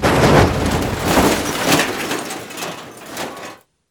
clamp2.wav